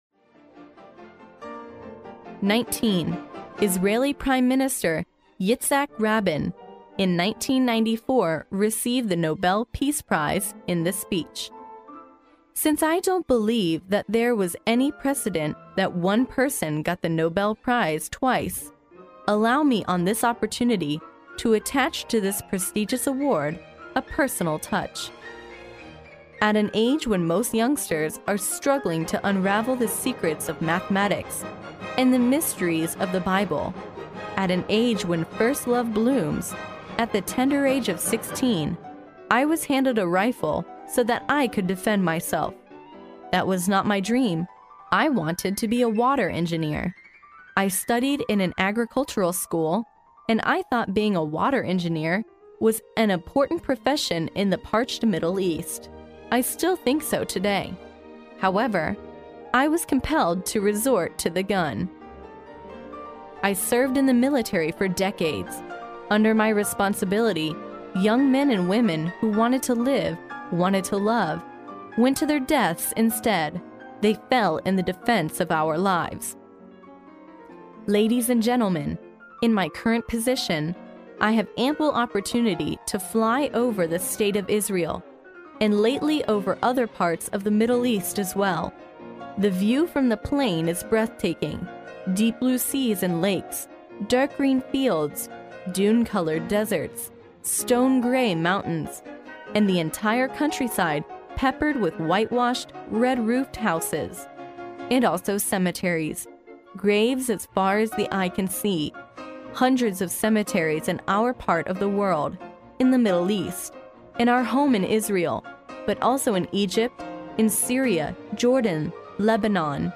历史英雄名人演讲 第68期:以色列总理拉宾1994年在接受诺贝尔和平奖时的演讲 听力文件下载—在线英语听力室